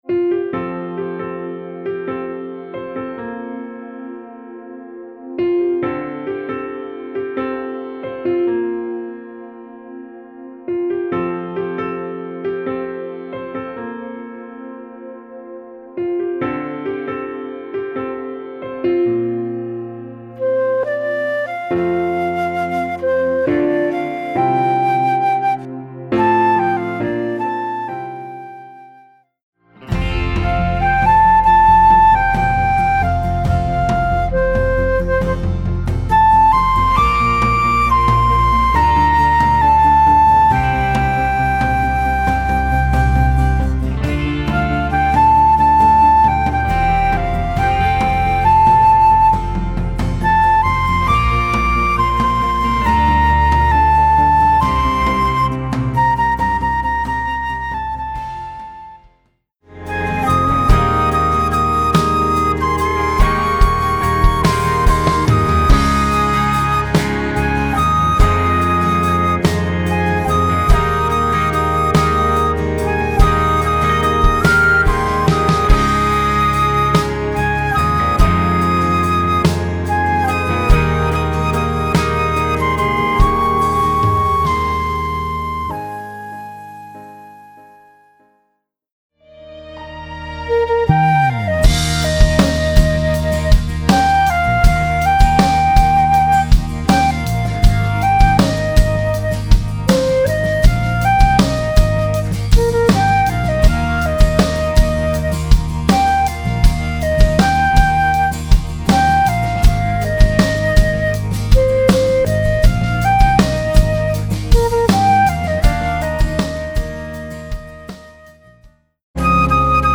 Voicing: Flute